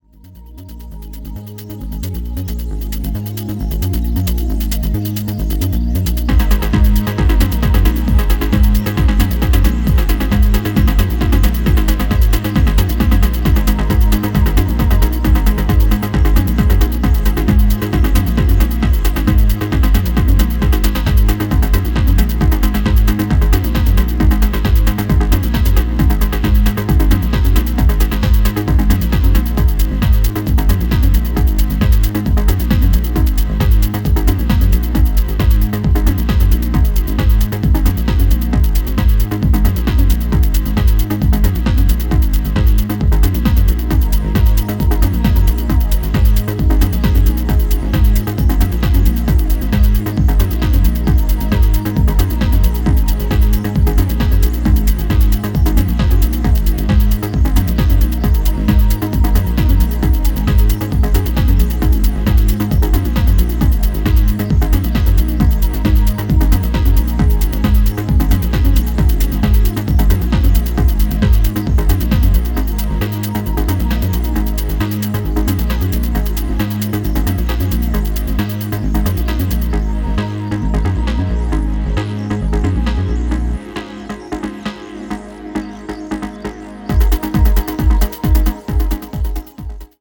トランシーでプログレッシヴなハウスを程よくミニマルに繰り広げていった、派手さやエグ味は控えめな仕上がりとなっています。